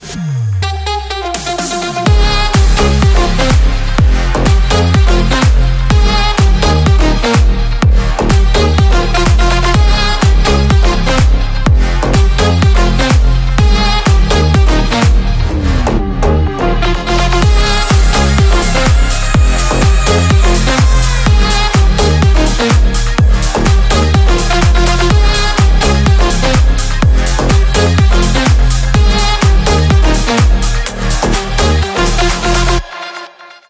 • Качество: 128, Stereo
зажигательные
без слов
Саксофон
Mashup